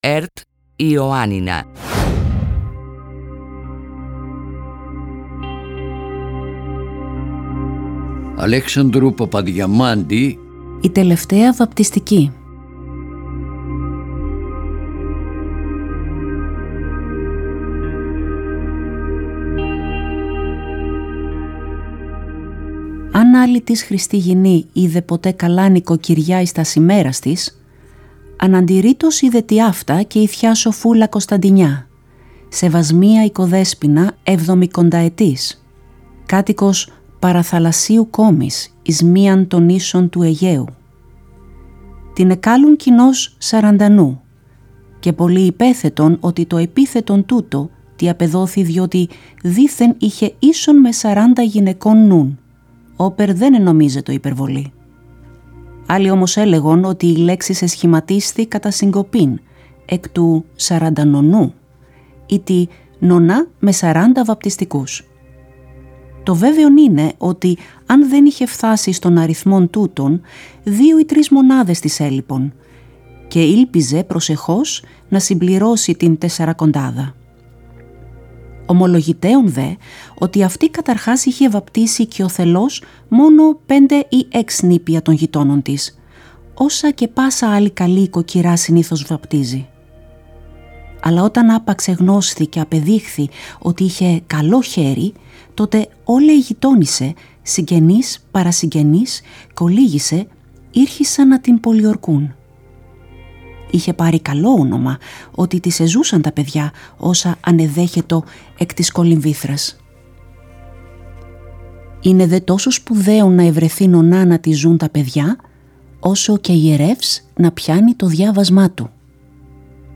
Η εκπαιδευτικός και ερασιτέχνης ηθοποιός
αφηγείται το διήγημα του Αλέξανδρου Παπαδιαμάντη «Η Τελευταία Βαπτιστική».